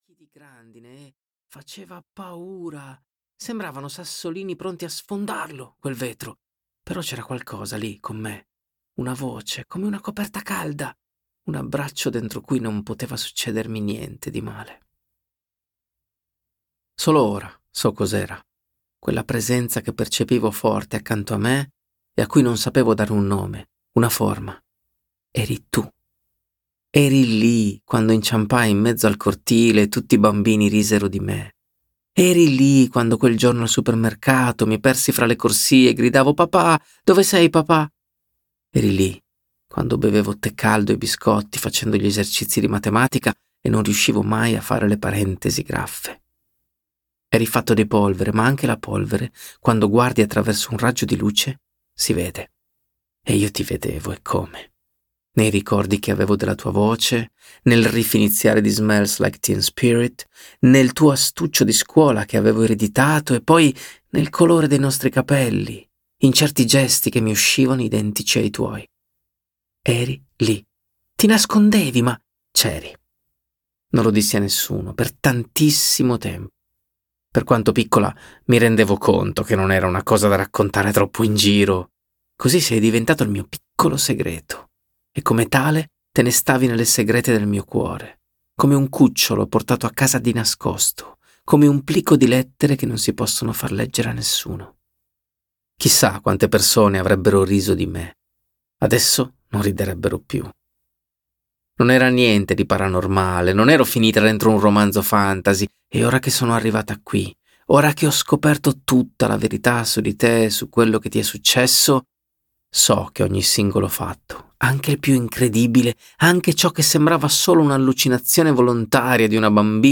"Quel posto che chiami casa" di Enrico Galiano - Audiolibro digitale - AUDIOLIBRI LIQUIDI - Il Libraio
• Letto da: Enrico Galiano